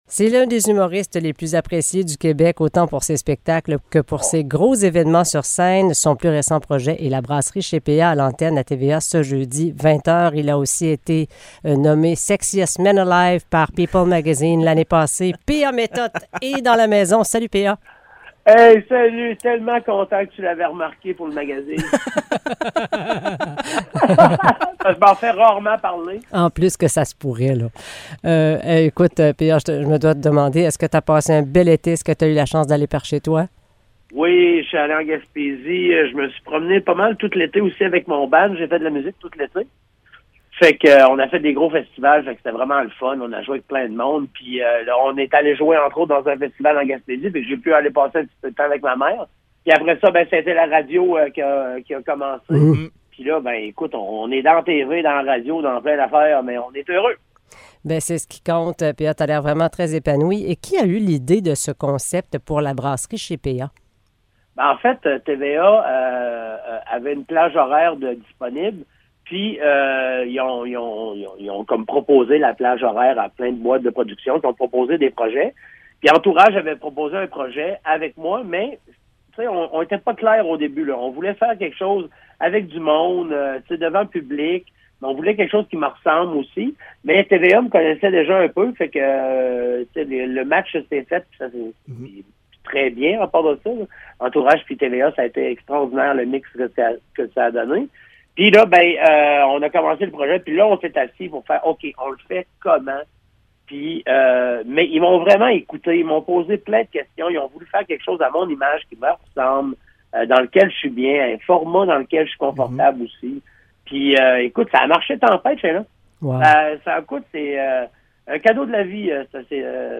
Entrevue avec l'humoriste P-A Méthot